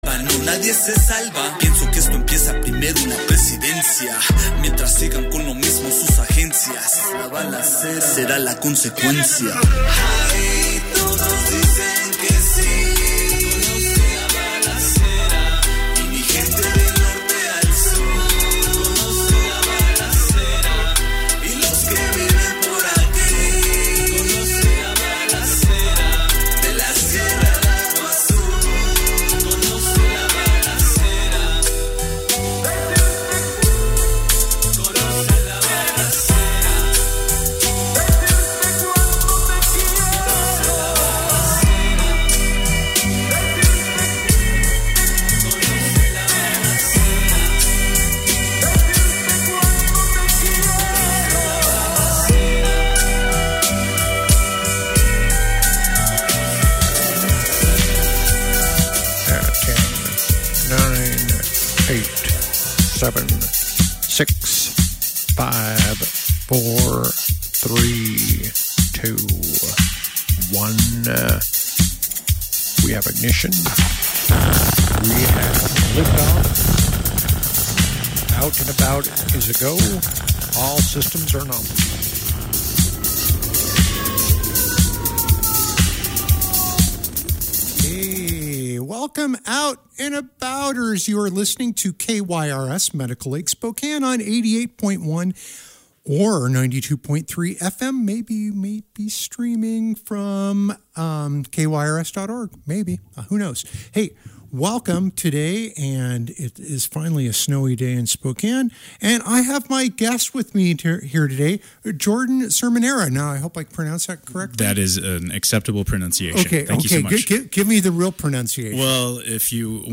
Out and About airs every Tuesday at 4 pm on KYRS - 88.1 and 92.3 fm.